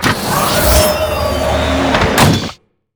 combatdronerecall.wav